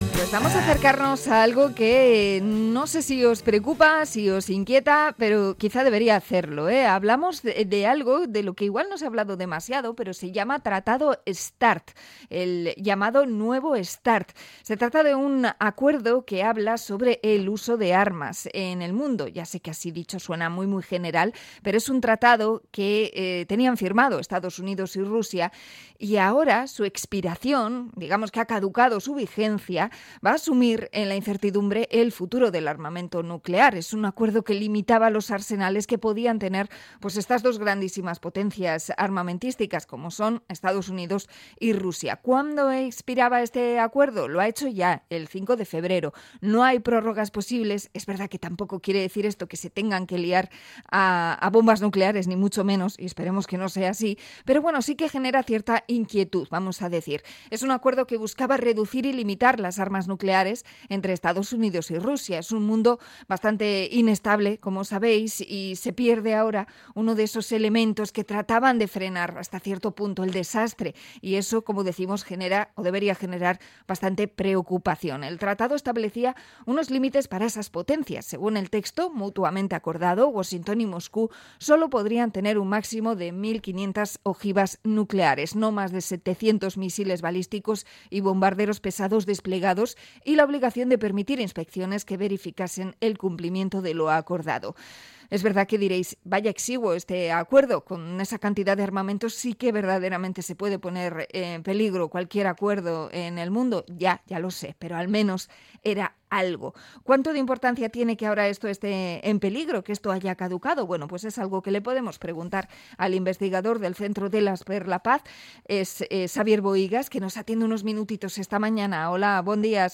Entrevista a investigador del Centre Delas sobre el fin del tratado START